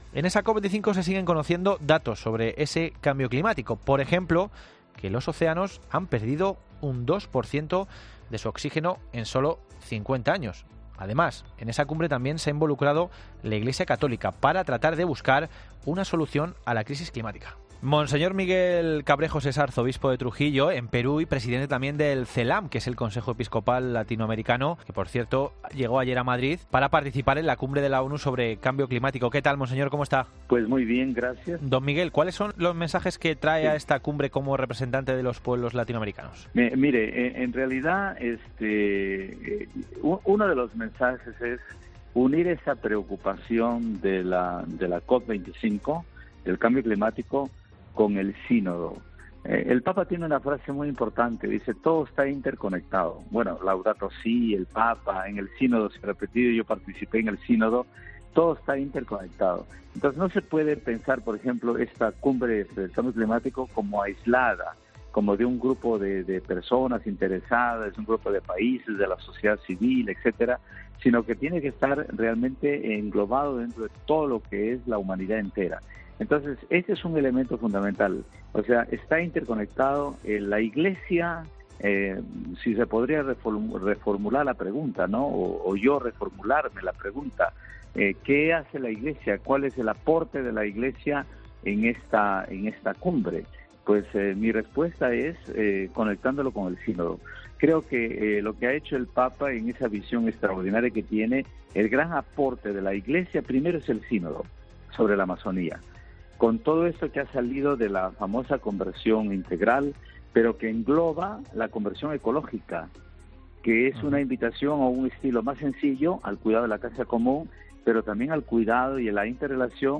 Unas horas después ha sido entrevistado en la Cadena COPE, donde ha dicho que uno de los propósitos es "unir la preocupación de la COP25 -la Cumbre del Clima- con el Sínodo", pues según ha manifestado, parafraseando al Papa, "todo está interconectado".